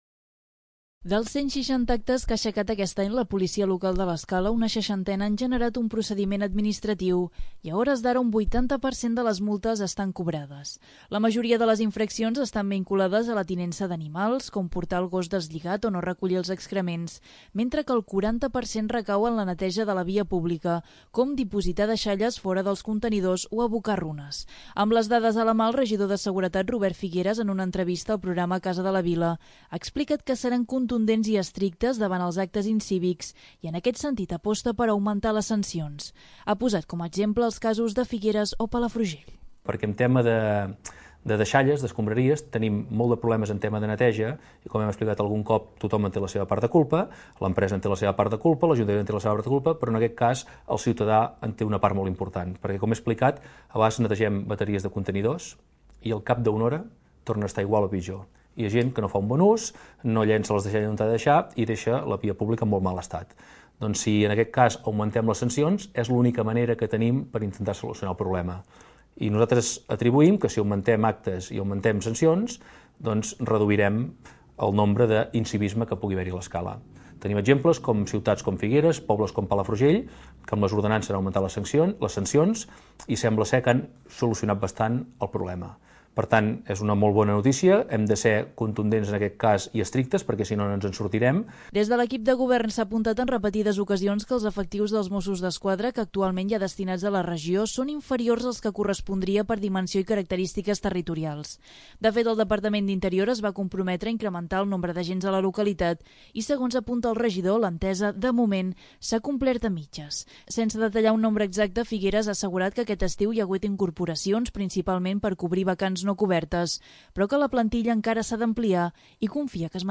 Amb les dades a la mà, el regidor de seguretat, Robert Figueres, en una entrevista al programa 'Casa de la Vila', ha explicat que seran 'contundents' i 'estrictes' davant els actes incívics, i en aquest sentit, aposta per 'augmentar' les sancions.